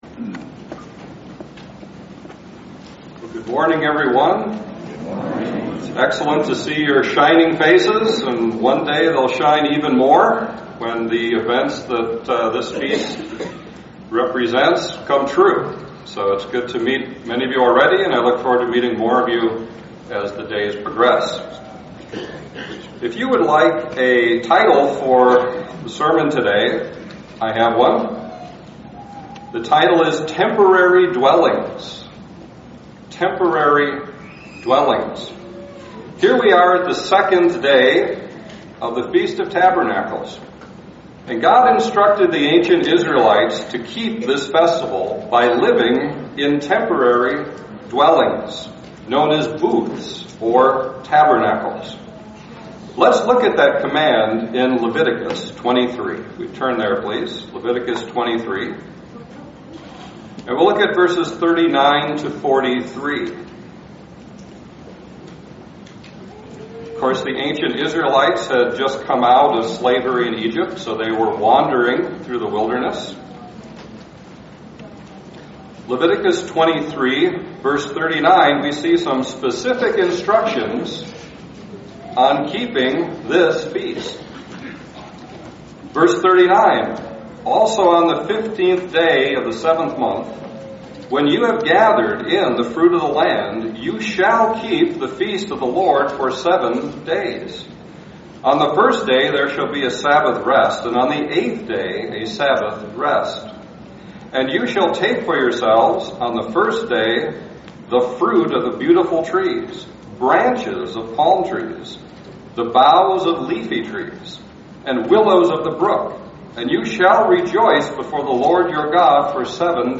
This sermon was given at the Oconomowoc, Wisconsin 2016 Feast site.